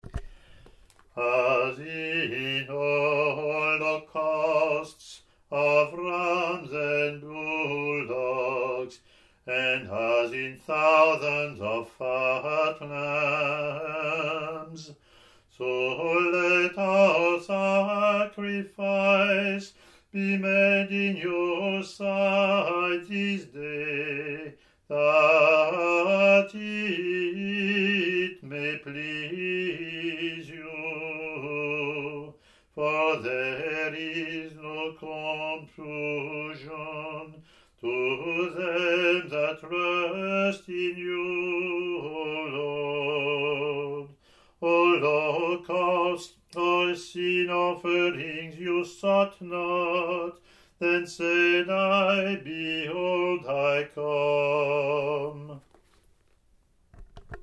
English antiphon – English verseLatin antiphon)
ot13-offertory-eng-pw.mp3